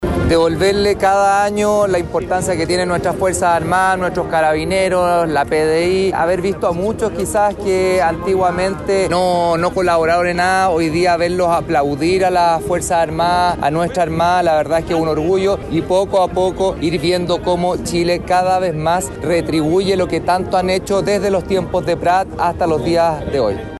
Con una multitud reunida en la Plaza Sotomayor de Valparaíso, se desarrolló este miércoles el tradicional desfile en conmemoración del 21 de mayo, fecha en que se recuerda el Combate Naval de Iquique y las Glorias Navales.
Por su parte, el presidente de la Cámara Baja, José Miguel Castro, destacó la participación ciudadana.
cu-jose-castro-pdte-diputados.mp3